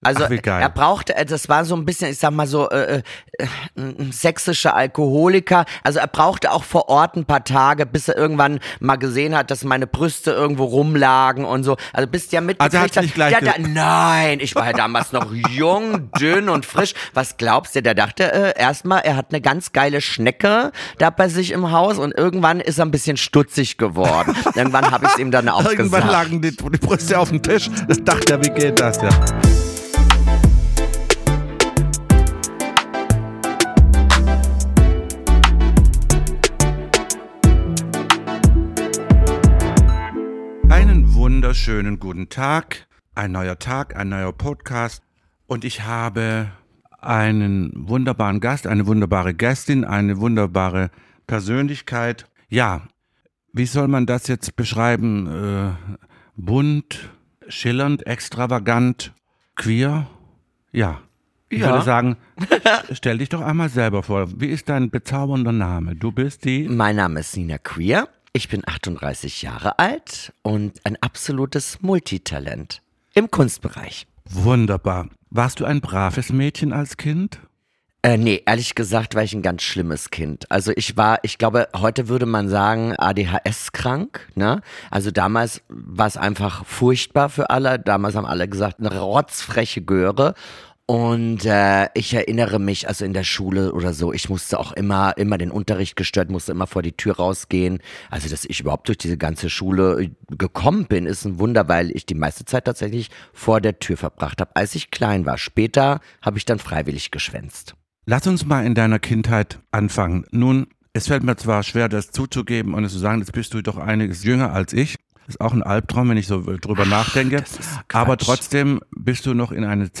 In dieser Episode führt Harald Glööckler ein spannendes, aufregendes und sehr offenes Gespräch mit Nina queer.